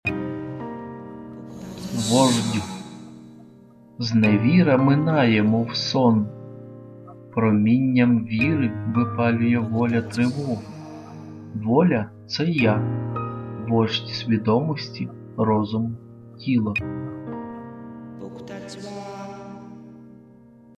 Тут, здебільшого, просто озвучені верлібри.